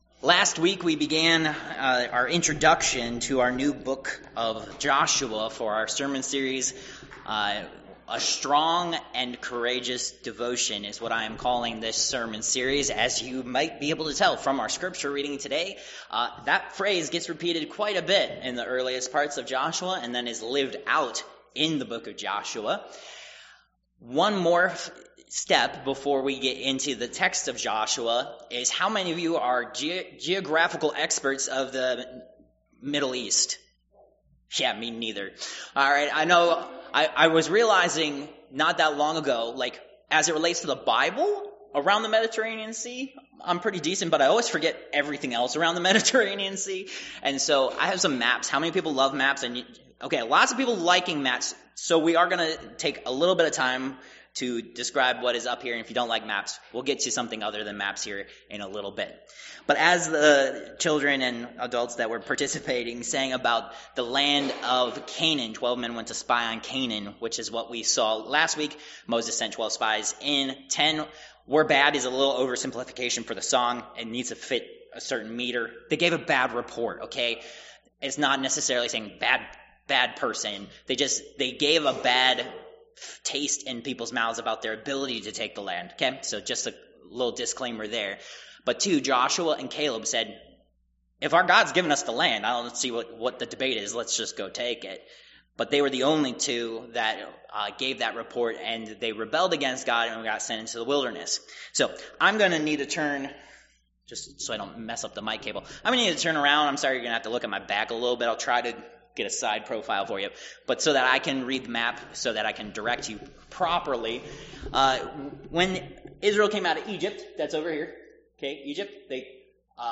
Joshua 1:1-9 Service Type: Worship Service See attachment for the maps that are discussed Download Files Notes « Who Is Joshua?